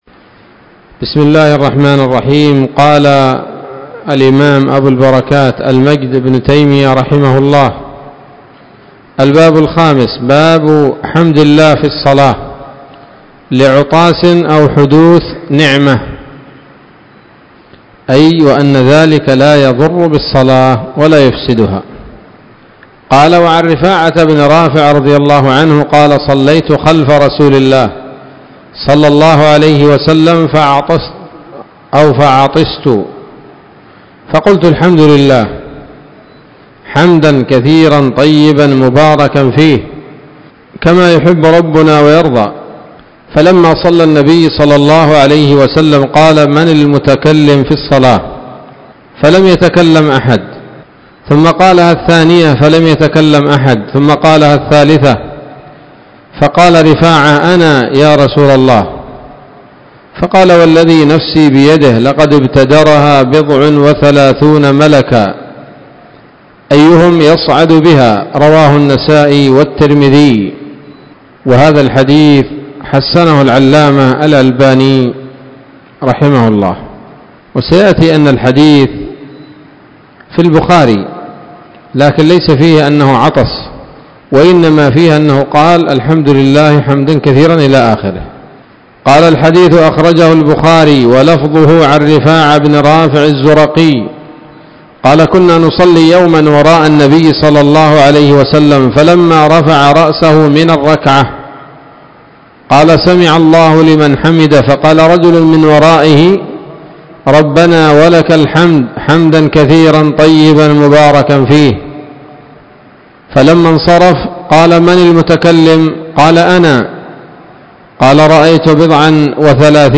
الدرس الثامن من أبواب ما يبطل الصلاة وما يكره ويباح فيها من نيل الأوطار